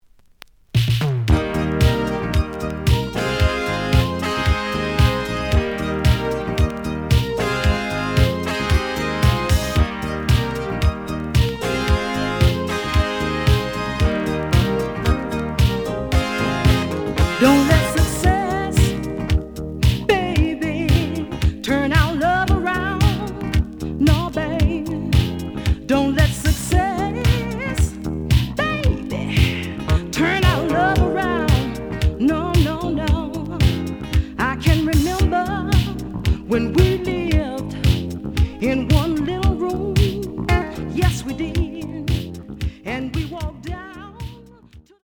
試聴は実際のレコードから録音しています。
The audio sample is recorded from the actual item.
●Genre: Soul, 80's / 90's Soul